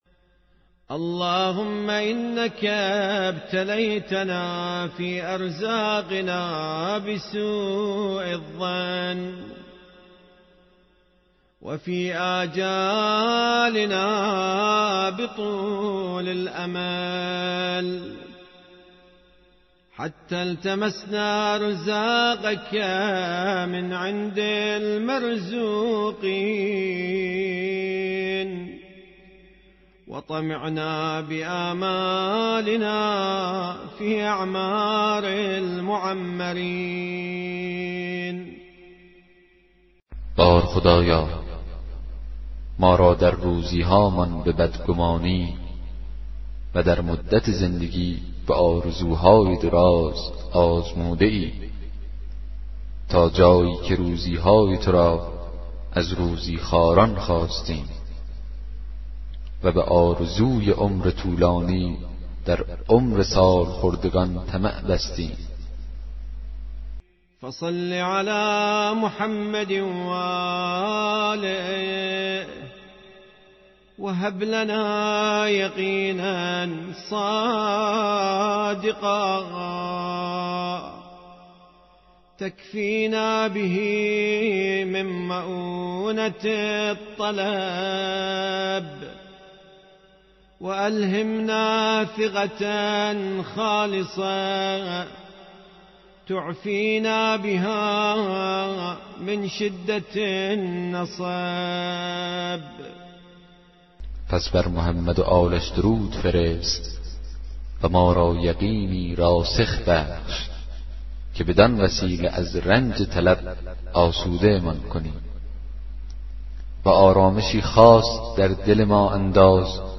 کتاب صوتی دعای 29 صحیفه سجادیه